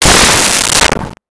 Rocket Launcher Sci Fi 01
Rocket Launcher Sci Fi 01.wav